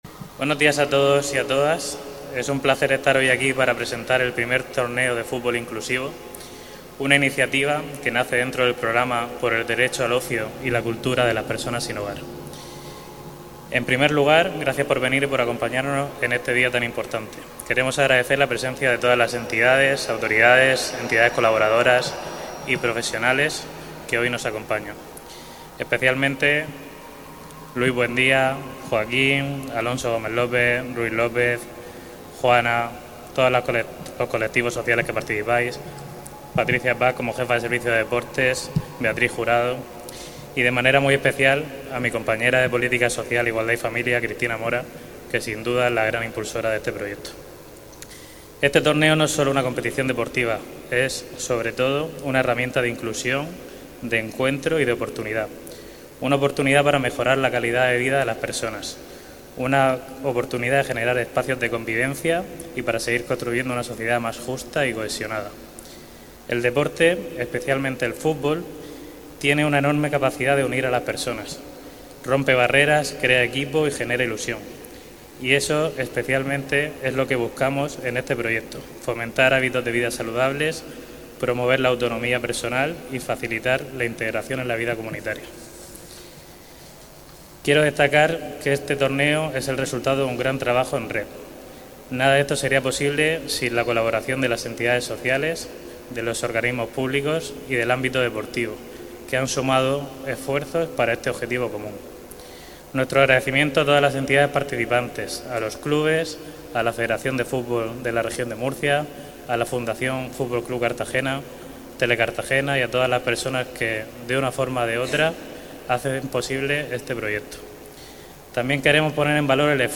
Enlace a Declaraciones de José Martínez y Cristina Mora sobre I Torneo de fútbol inclusivo
El acto ha contado con la presencia del concejal de Deportes, José Martínez, y la edil de Política Social, Igualdad y Familia, Cristina Mora, quienes han destacado el valor del deporte como herramienta de integración, convivencia y promoción de hábitos de vida saludables.